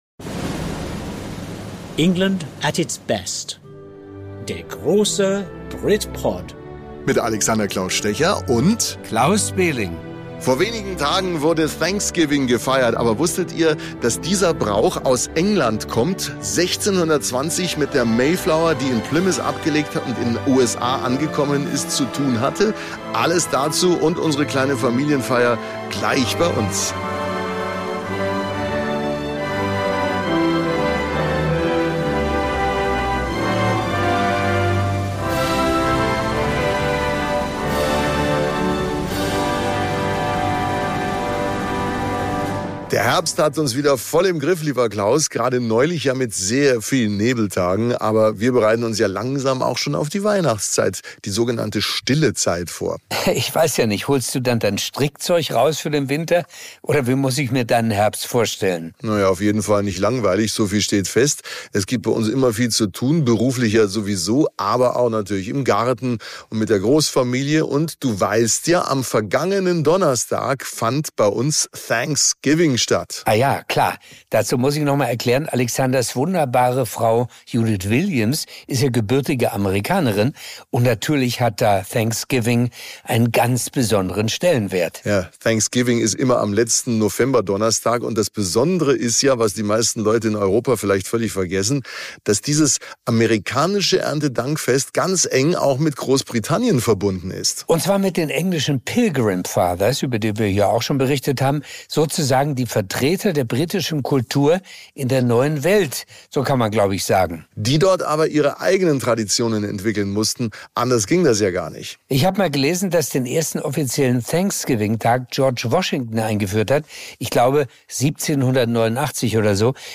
Dabei bleibt das Podcast-Mikrofon eingeschaltet.